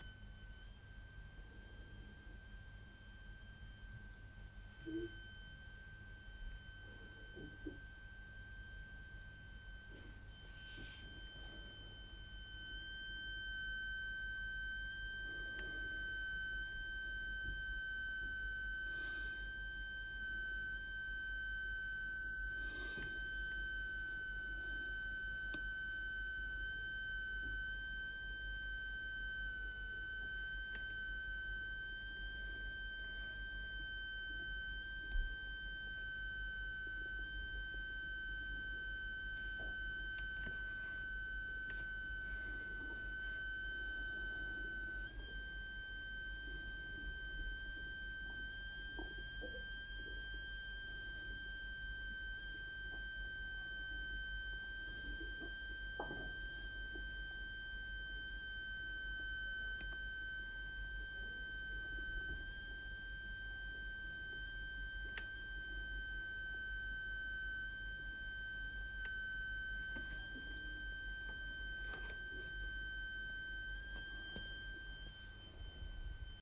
Ik hoor sinds een paar maanden elke nacht en ochtend een hele irritante pieptoon in mijn slaapkamer. In het begin was het af en toe, heel kort en niet zo hard, en nu is het echt hard en word ik er zelfs wakker van.
Er zit een soort uitstulping in mijn muur, waar het vandaan komt.
Dit geluid hoor ik als de verwarming ontlucht moet worden.